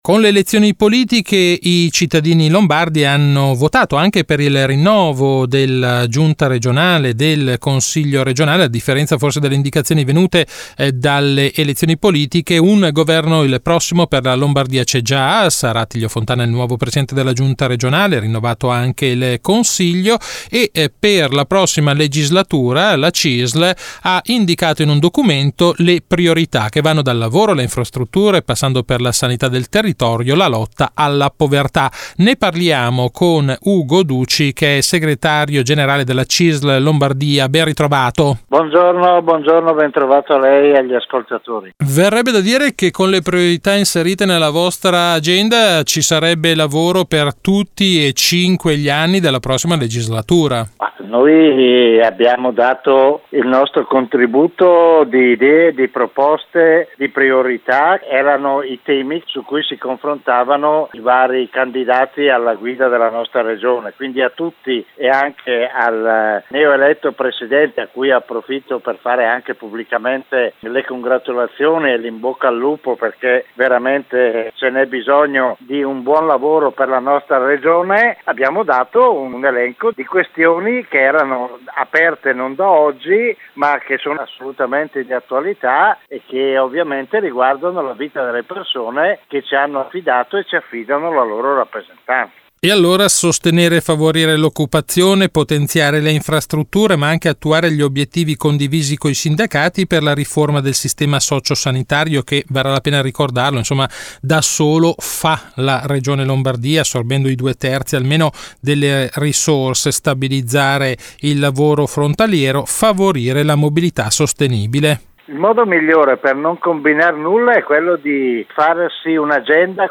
Di seguito l’ultima puntata di RadioLavoro, la rubrica d’informazione realizzata in collaborazione con l’ufficio stampa della Cisl Lombardia e in onda tutti i giovedì alle 18.20 su Radio Marconi in replica il venerdì alle 12.20.